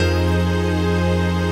CHRDPAD049-LR.wav